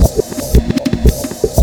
FXLOOPREV1-L.wav